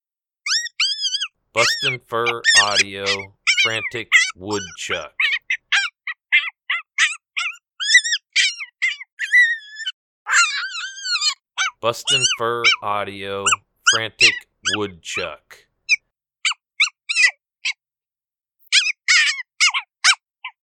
Juvenile Groundhog in distress.
BFA Frantic Woodchuck Sample.mp3